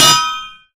anvil_use.ogg